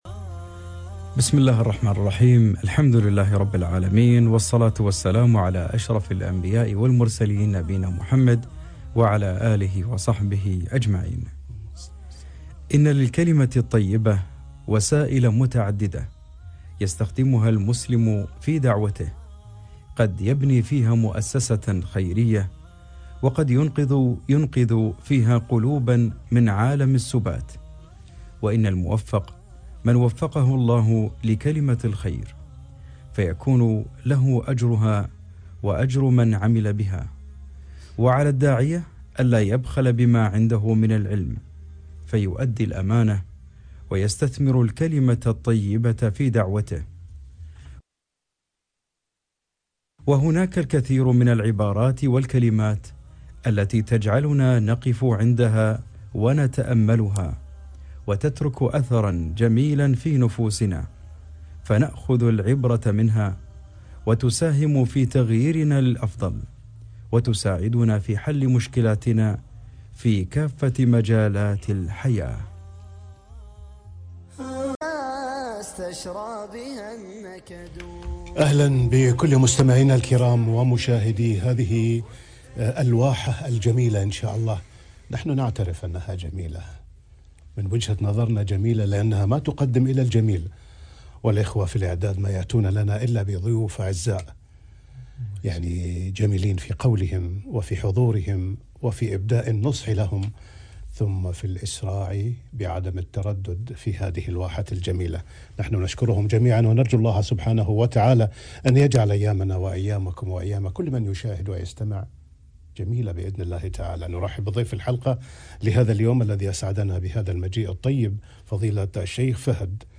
الكلمة الطيبة - لقاء إذاعي